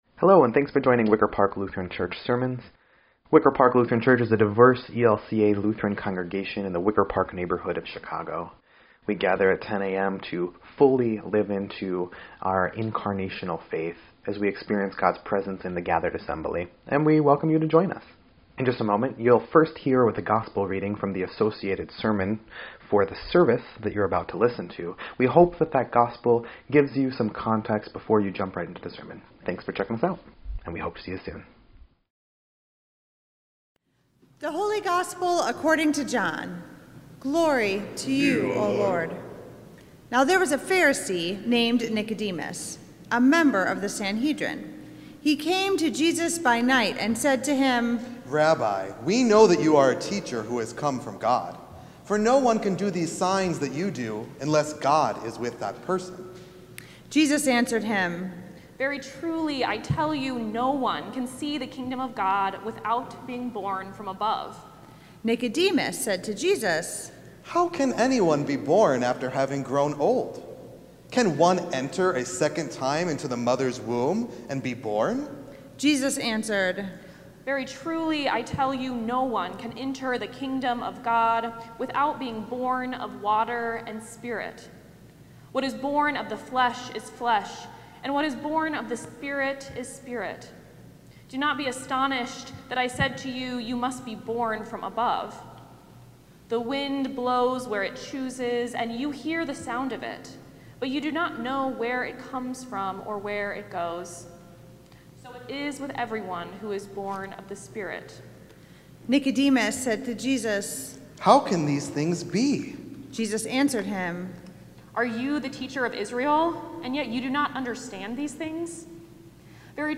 3.1.26-Sermon_EDIT.mp3